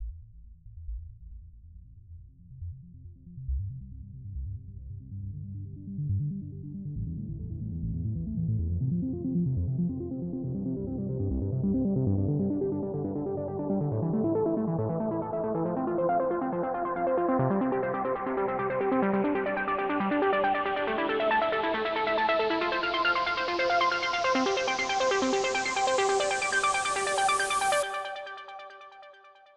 Trance Synth Demo
trance_synth.wav